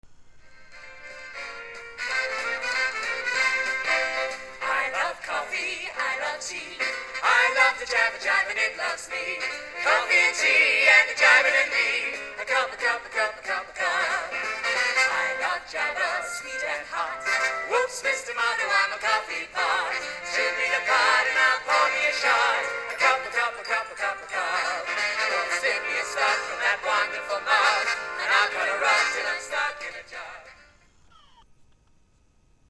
live recording
popular 40's swing tunes